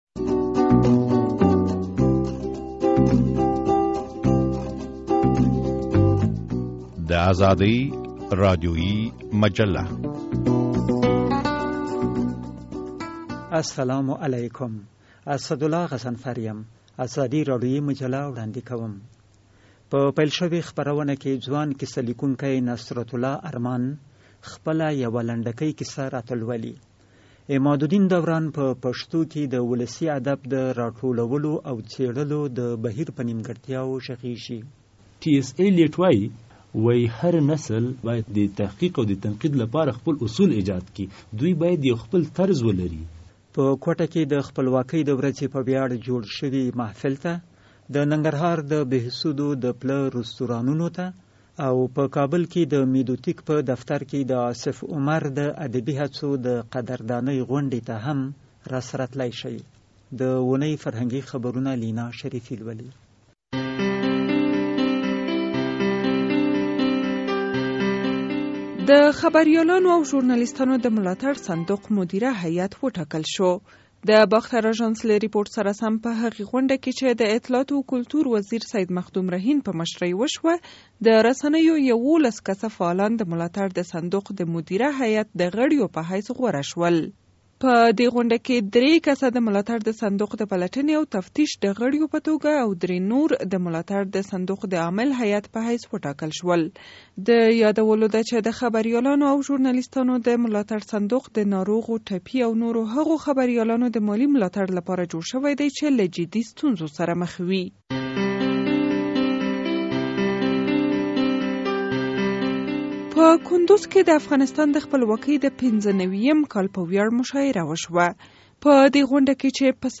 مرکه اورئ.